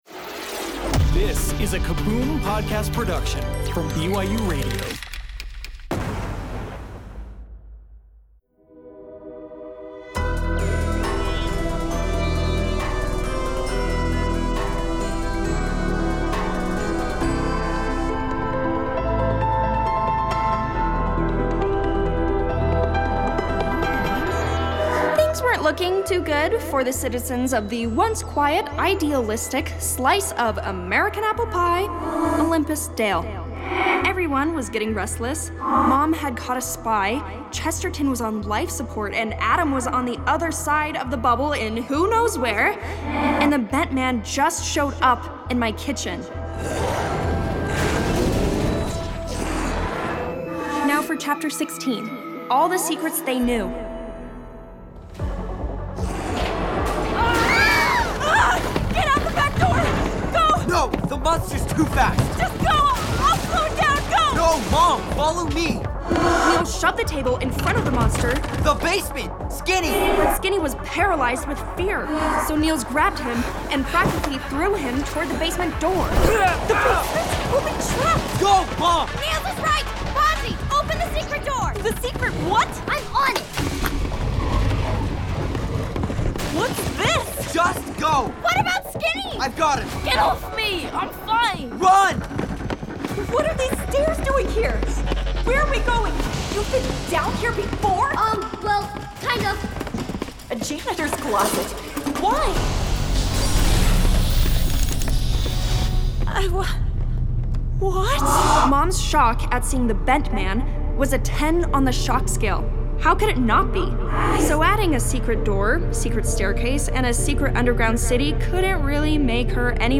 Bedtime Stories Audio Drama BYUradio Sci-Fi / Fantasy Stories Content provided by BYUradio.